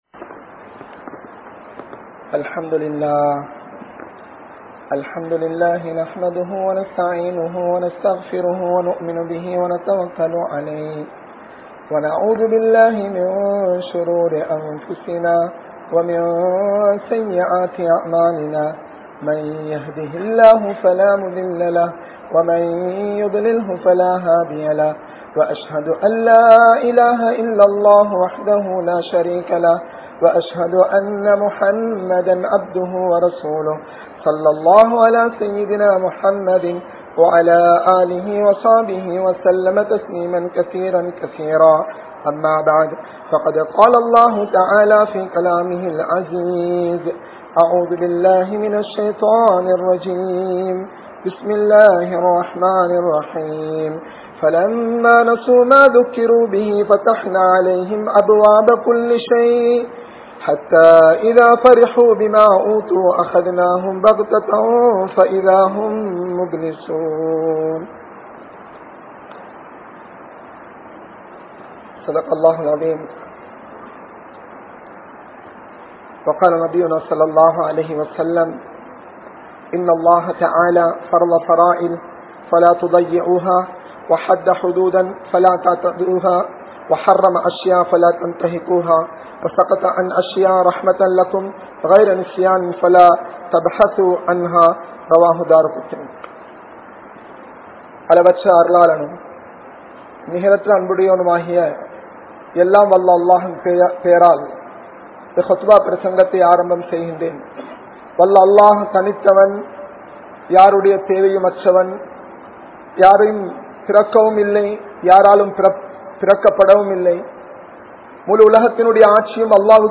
Allah Mannikkaatha Manitharhal (அல்லாஹ் மண்ணிக்காத மனிதர்கள்) | Audio Bayans | All Ceylon Muslim Youth Community | Addalaichenai